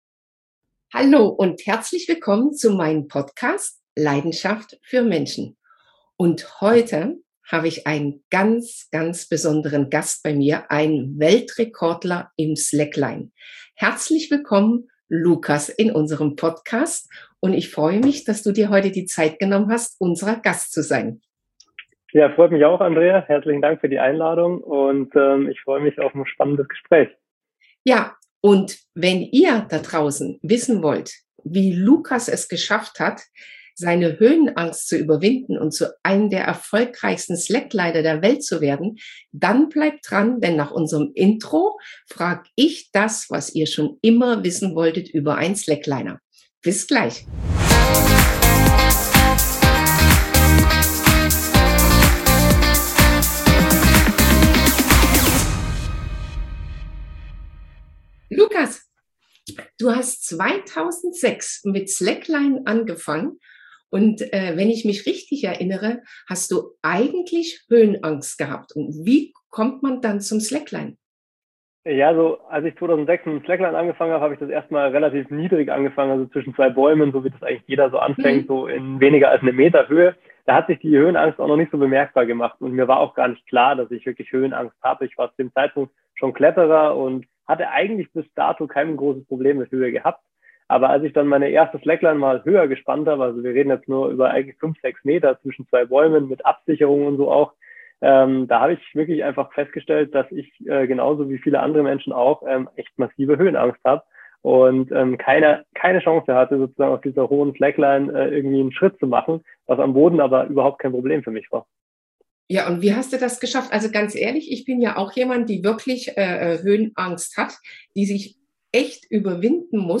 im Interview!